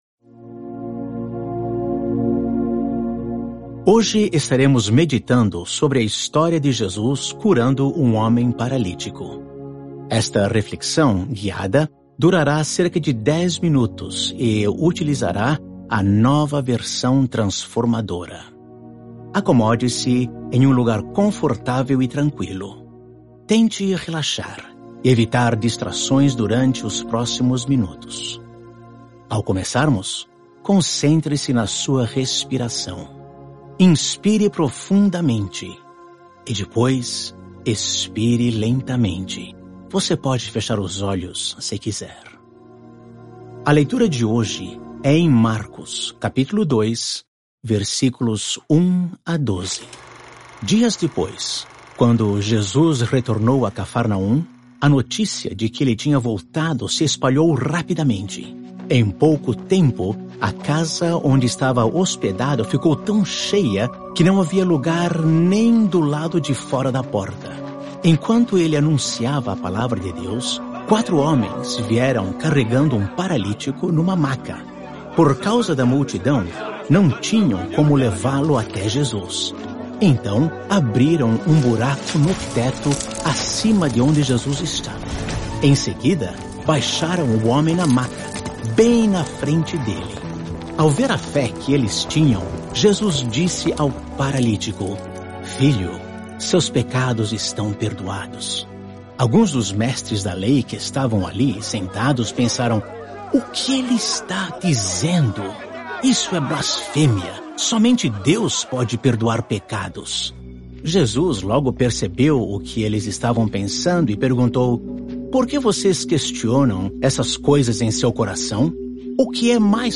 Hoje, gostaríamos muito que se juntasse a nós nesta imersiva leitura da Bíblia.